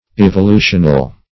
Search Result for " evolutional" : The Collaborative International Dictionary of English v.0.48: Evolutional \Ev`o*lu"tion*al\, a. Relating to evolution.